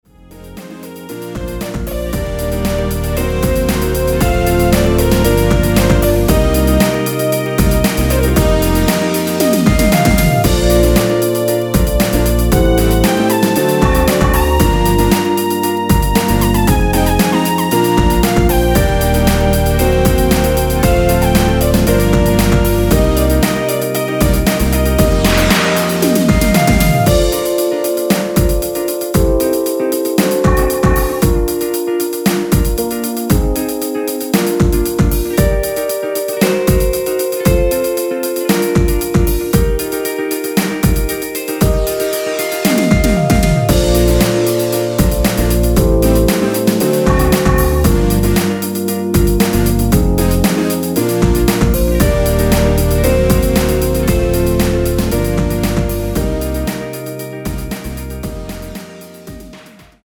원키에서(-1)내린 2절 삭제한 편곡 MR 입니다.(아래의 가사및 미리듣기 확인)
Bb
앞부분30초, 뒷부분30초씩 편집해서 올려 드리고 있습니다.
중간에 음이 끈어지고 다시 나오는 이유는